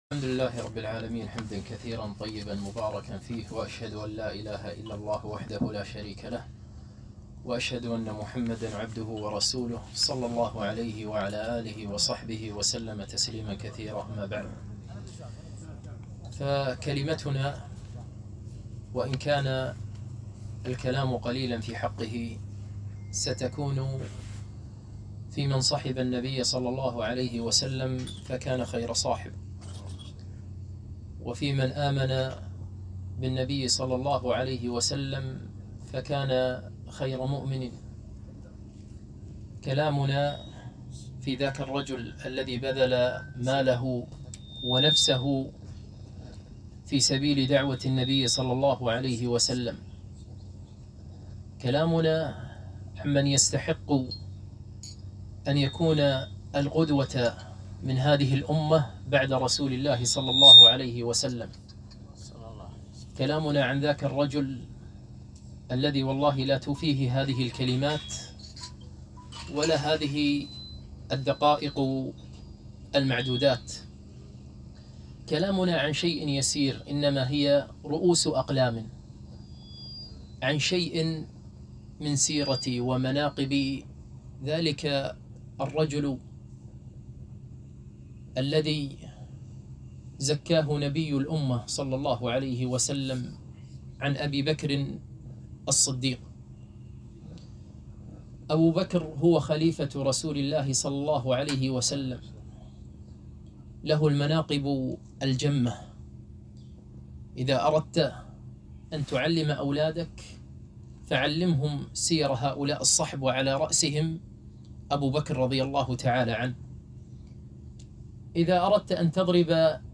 محاضرة - أبوبكر الصديق رضي الله عنه - دروس الكويت